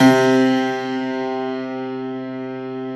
53a-pno06-C1.aif